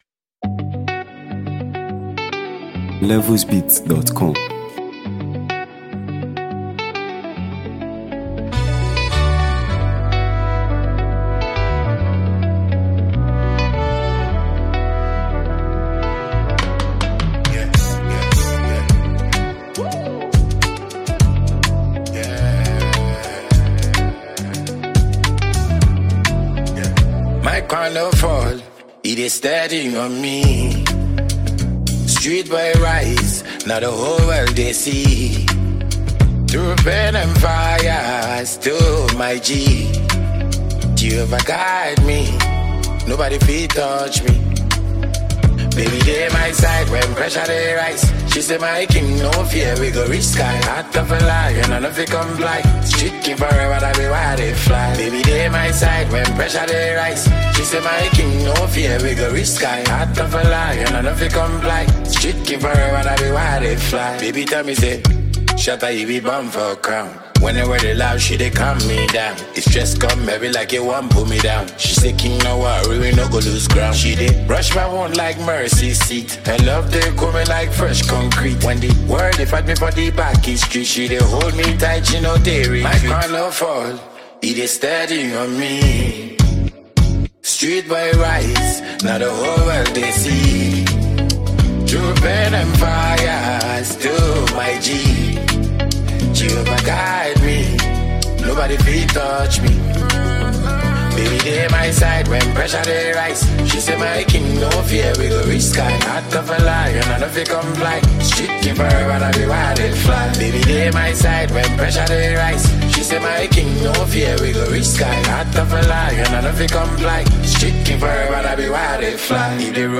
Ghana Music 2025 3:15